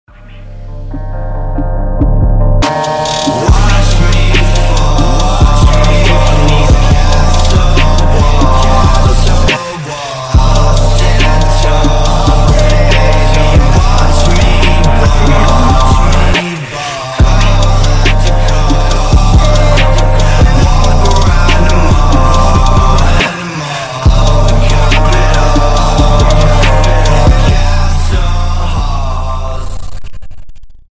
cloud rap
trap